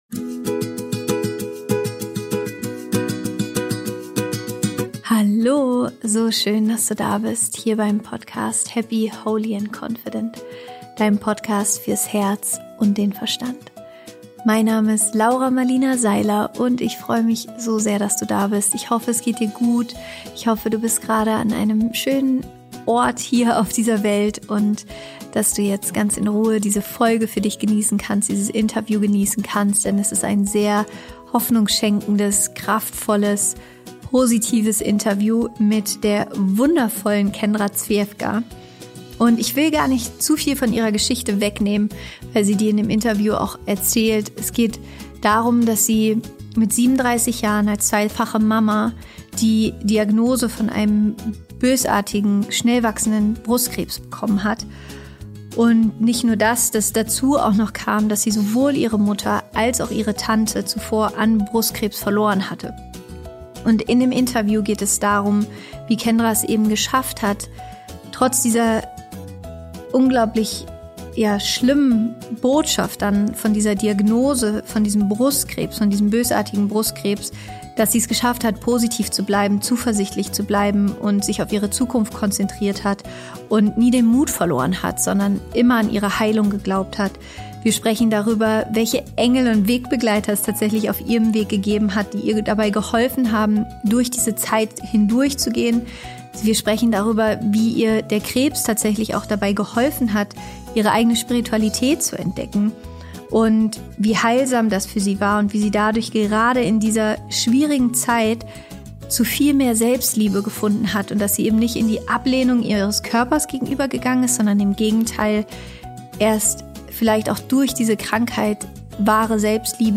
Genau darüber spreche ich auch mit meinem heutigen Interviewgast in der neuen Podcastfolge.